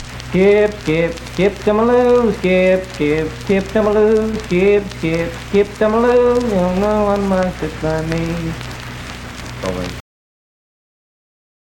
Unaccompanied vocal performance
Dance, Game, and Party Songs
Voice (sung)
Spencer (W. Va.), Roane County (W. Va.)